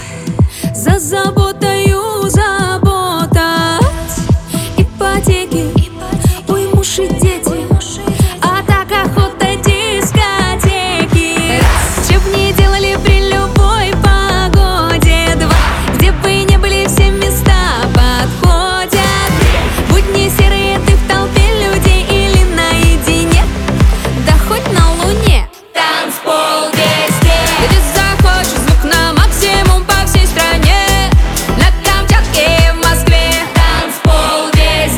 Жанр: Поп музыка / Танцевальные / Русский поп / Русские